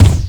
Kick 17.wav